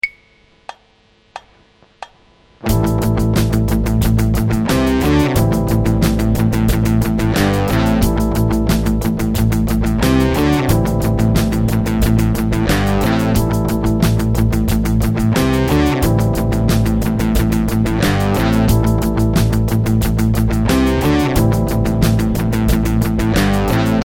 The groove used here is based around the drums playing quick 16th notes on the hi-hat. The chords are A minor with a little bit of C D and G major.
The first idea is a simple 16th note rhythm matching the high-hat and additional 8th note C D G A notes matching the bass at the end of the bars.
These 16th notes are palm muted while the other 8th notes aren’t to help you create accents.